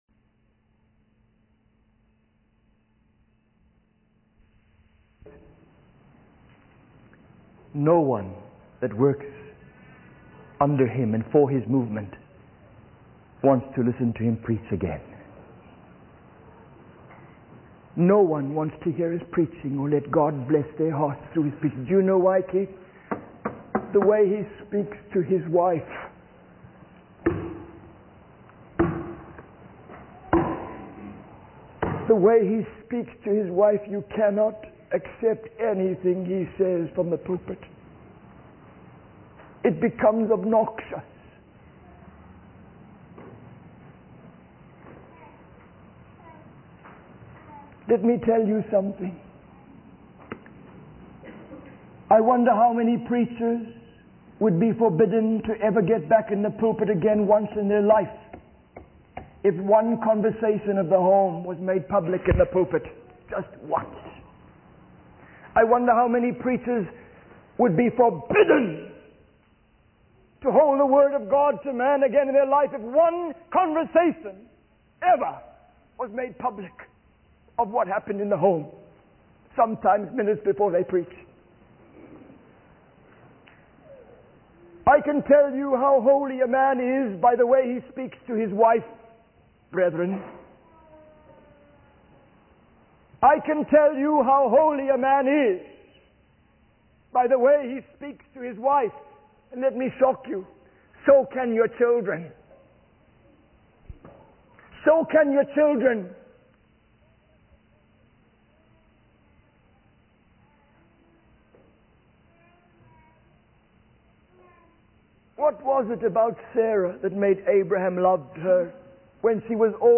In this sermon, the preacher emphasizes the importance of a man's behavior towards his wife as a reflection of his holiness. He suggests that if the conversation between a preacher and his wife before preaching was made public, many preachers would be forbidden from preaching again.